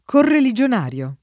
correligionario [ korreli J on # r L o ]